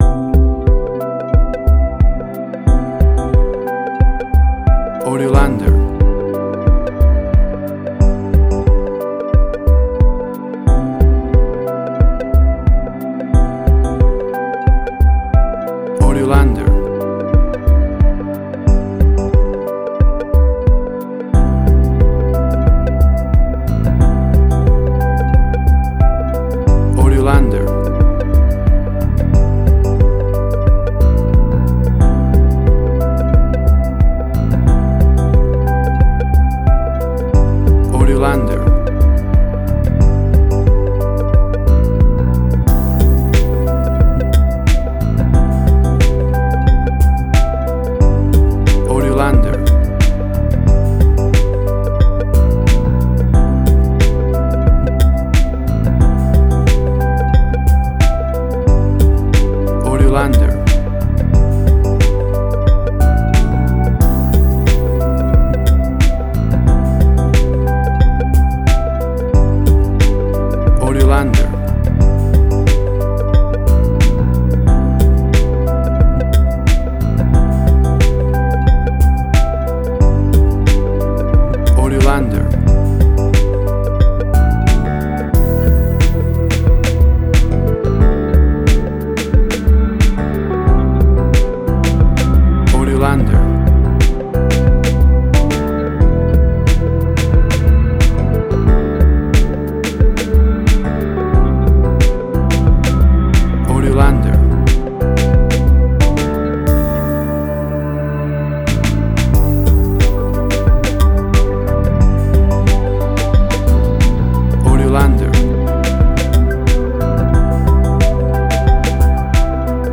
WAV Sample Rate: 16-Bit stereo, 44.1 kHz
Tempo (BPM): 90